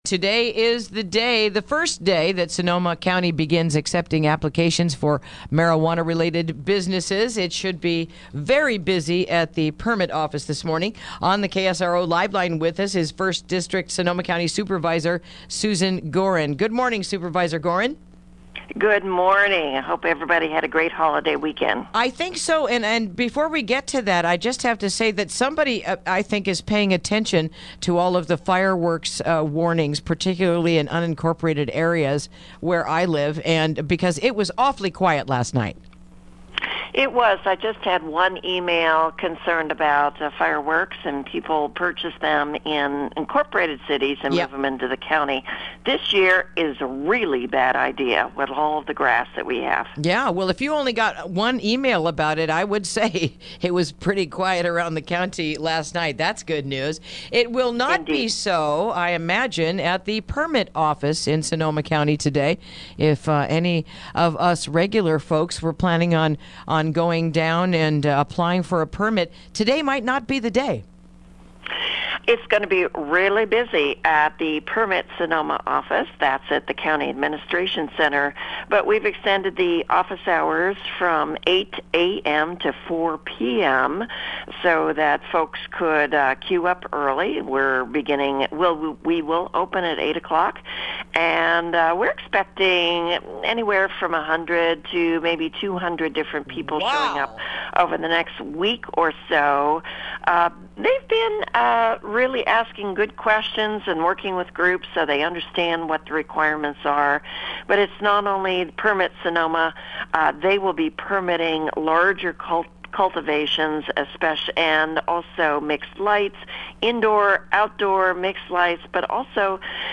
Interview: Marijuana Permit Applications Begin Today
1st District Supervisor Susan Gorin, joins us to talk about the what to expect today.